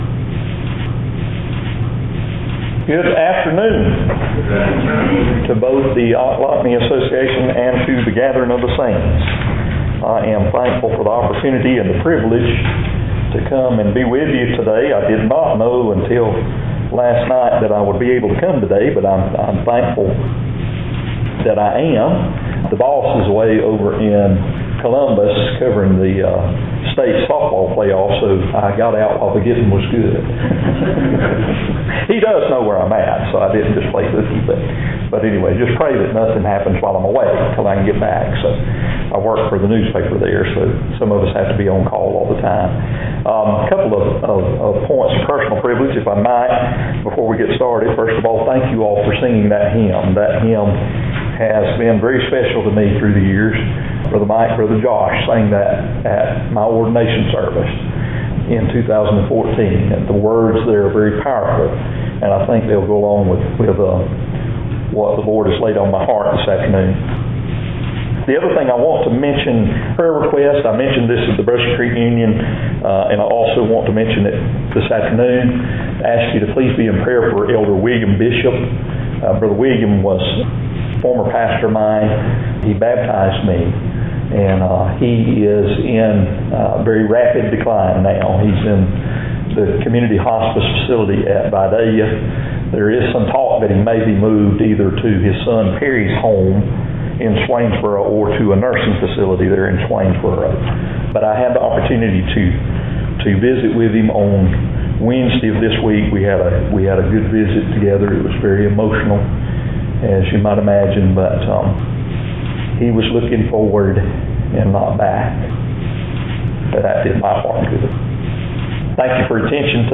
Christ’s Prayer For His Church Jan 6 In: Sermon by Speaker